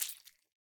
tbd-station-14/Resources/Audio/Effects/Footsteps/slime3.ogg at d1661c1bf7f75c2a0759c08ed6b901b7b6f3388c
slime3.ogg